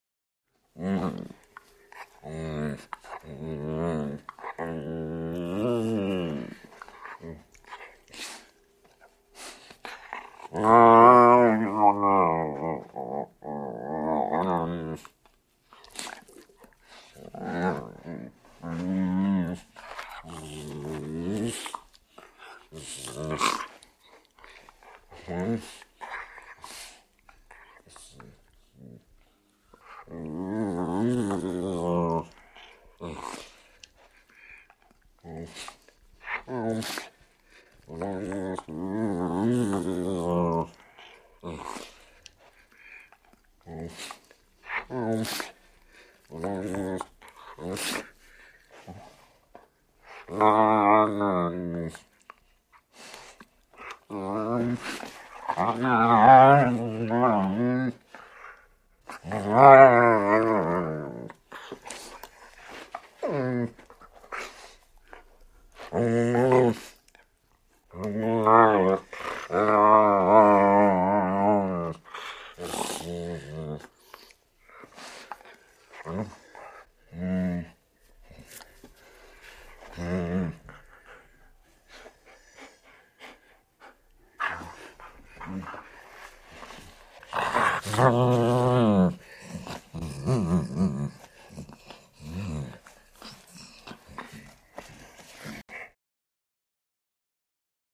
Dog, German Shepherd Growls And Breaths. Vocal-like, Playful Growls And Breaths. Close Perspective.